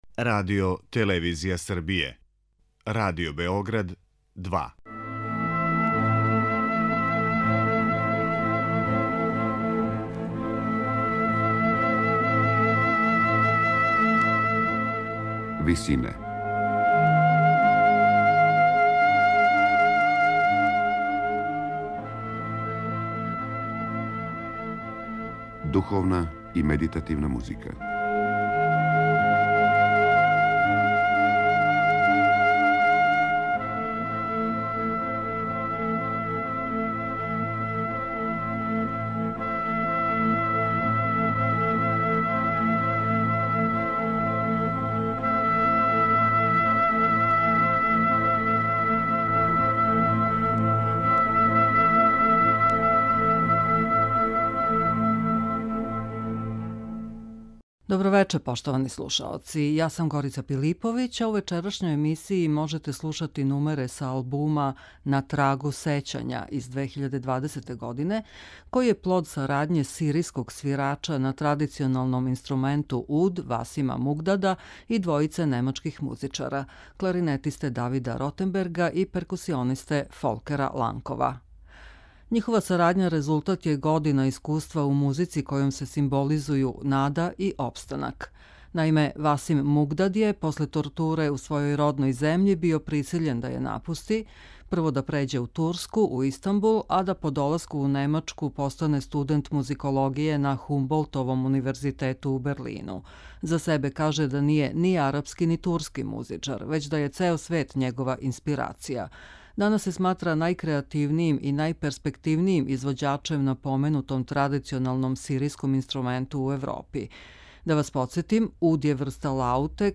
сиријског свирача на традиционалном инструменту уд
двојице немачких музичара - кларинетисте
перкусионисте